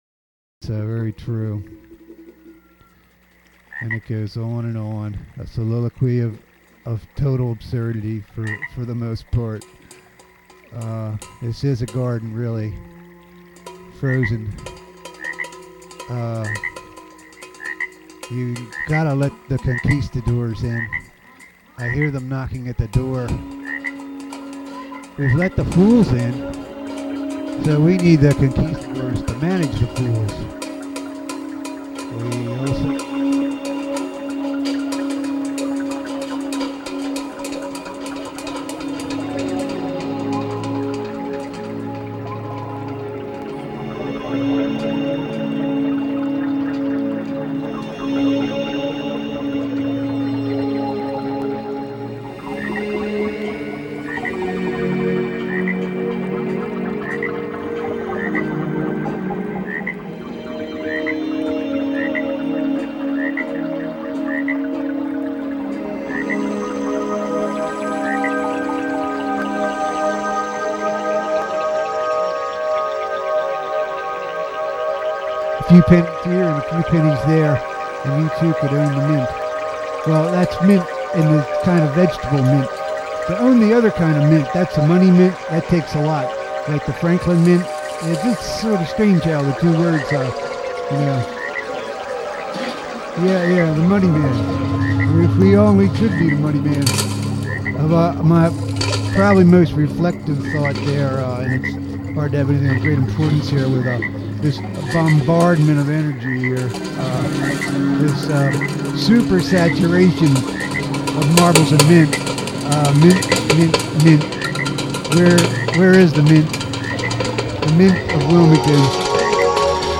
A variety of spoken improvisations or streams of vocalese mx with music of the moment and percussions of the past.